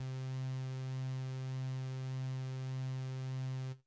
BAL Backing Synth C2.wav